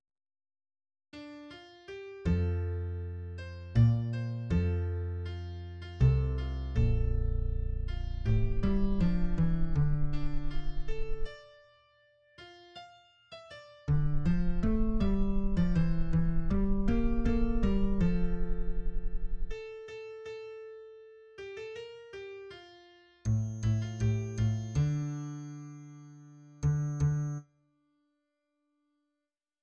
} \new Staff \with {midiInstrument = #"acoustic bass"} << \set Staff.midiMinimumVolume = #4.7 \set Staff.midiMaximumVolume = #5.9 \set Score.currentBarNumber = #24 \bar "" \key c \major \time 3/4 \new Voice = "two" \relative c '{ \voiceOne r2.